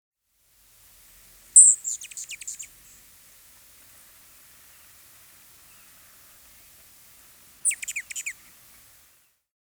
А это колибри Аллена издает такой птичий звук